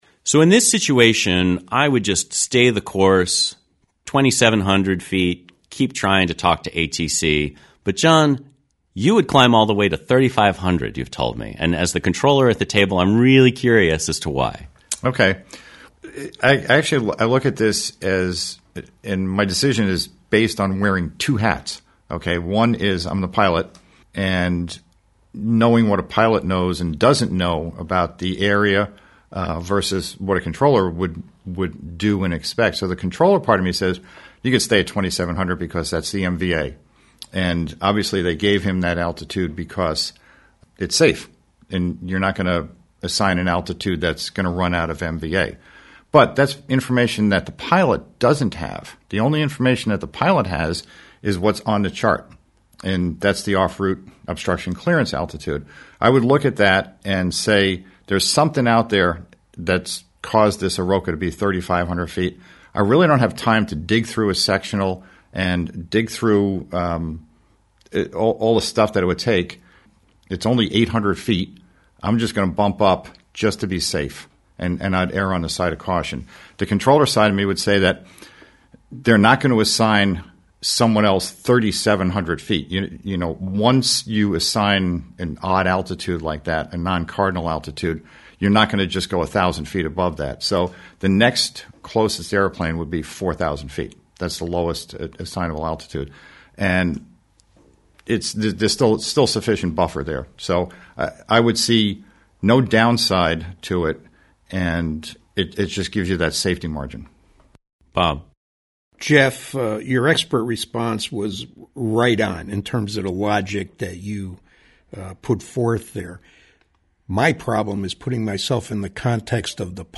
#85 NORDO below the OROCA Round Table.mp3